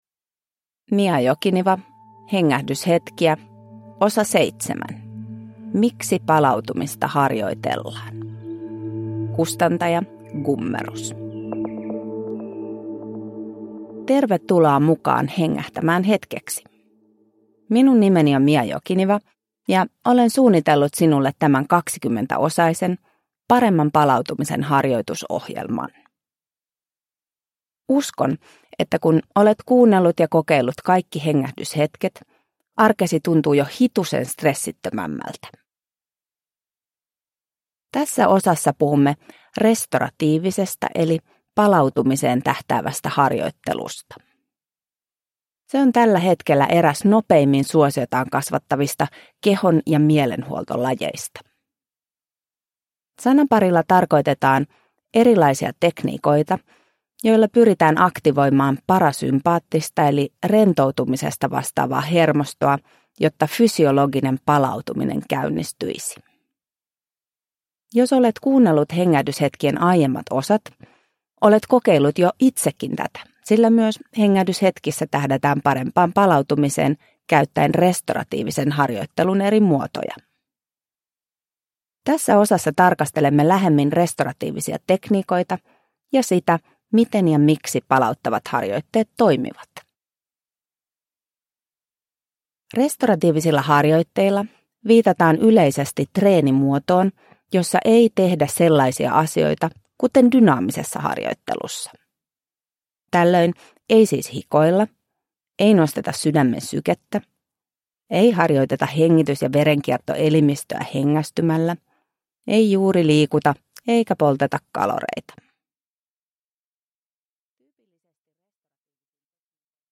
Hengähdyshetkiä (ljudbok) av Mia Jokiniva